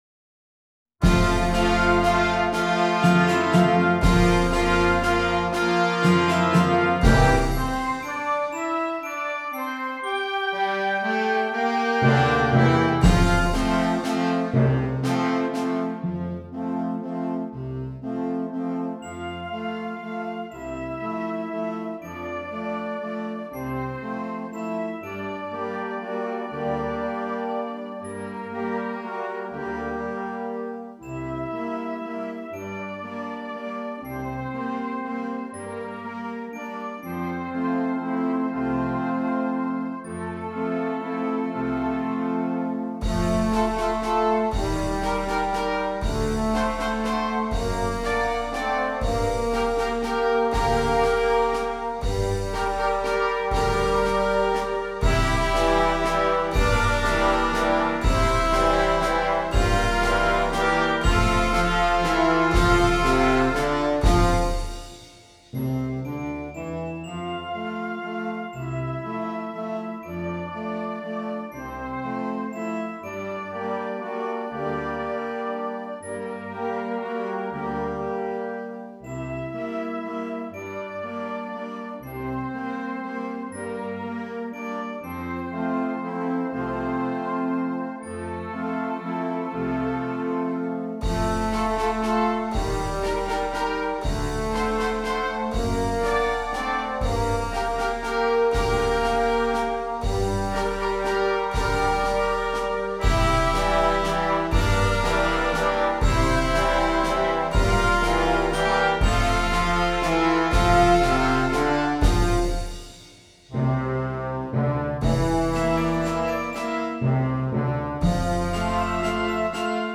• Flauta
• Oboe
• Clarinete en Bb
• Saxofón Alto
• Trompeta en Bb
• Trombón
• Tuba
• Glockenspiel